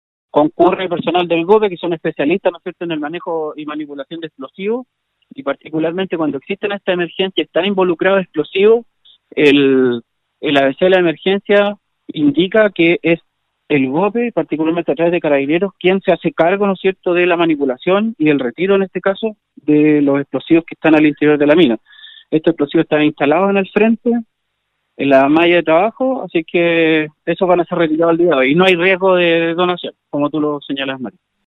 Javier Sáez, director regional de Onemi Atacama explicó a Nostàlgica el trabajo que se realiza en Mina Esperanza, entregando detalles de la maniobra, la cual está supervisada por el director regional de Sernageomín: